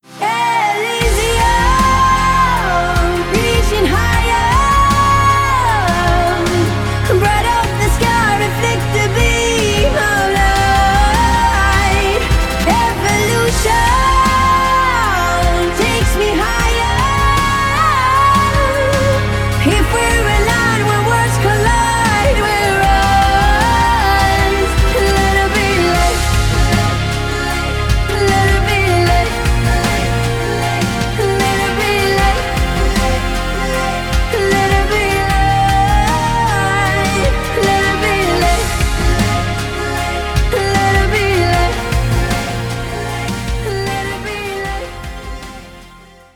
• Качество: 320, Stereo
женский вокал